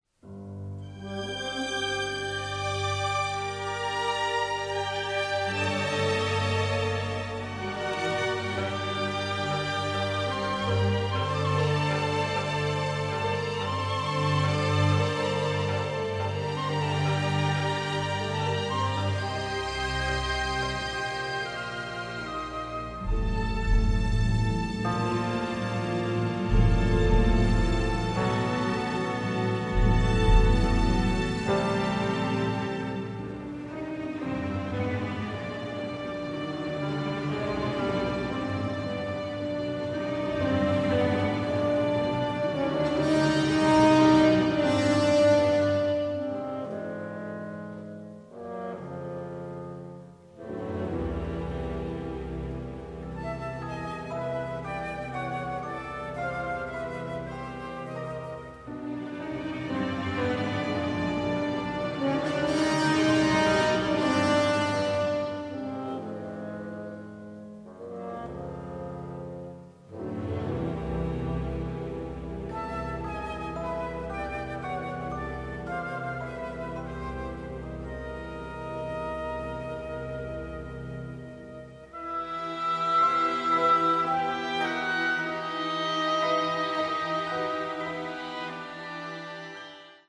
original motion picture score
the score is operatic in its power